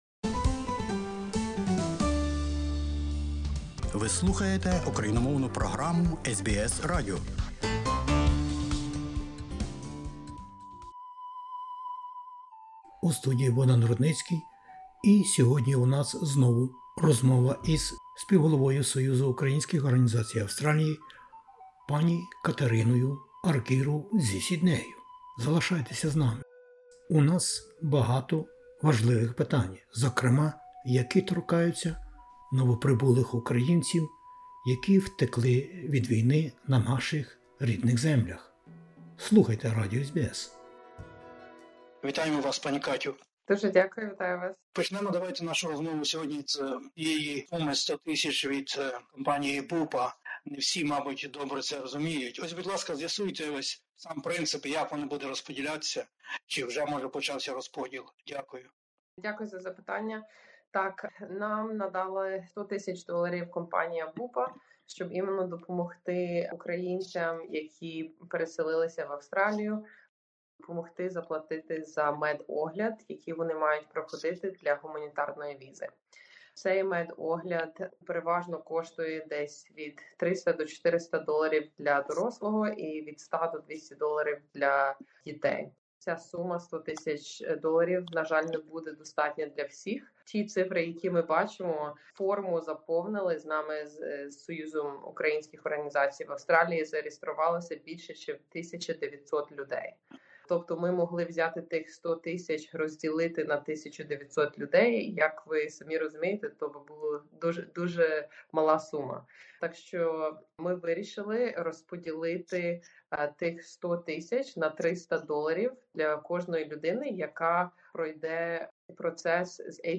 В інтерв'ю для SBS Українською